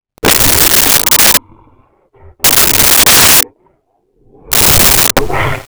Creature Breath 04
Creature Breath 04.wav